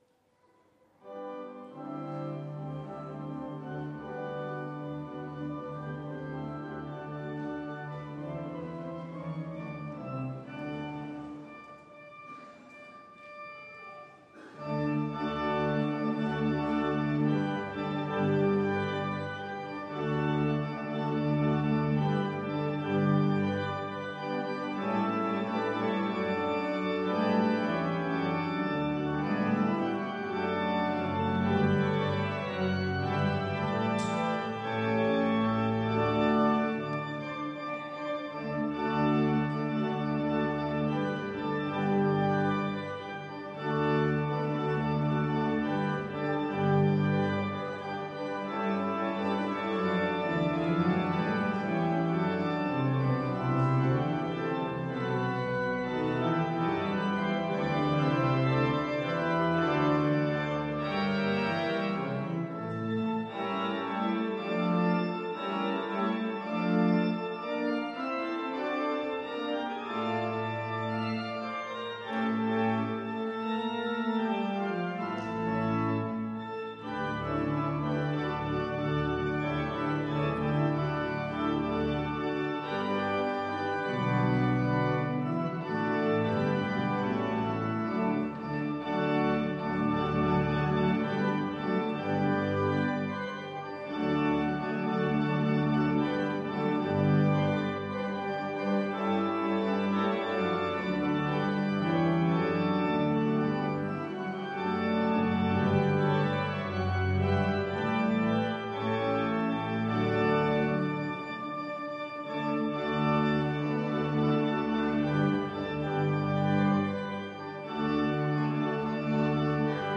Orgelstück zum Ausgang Ev.-Luth.
Audiomitschnitt unseres Gottesdienstes am 11.Sonntag nach Trinitatis 2024